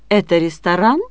ton haut sur le mot porteur de l'interrogation, puis ton bas descendant brusquement.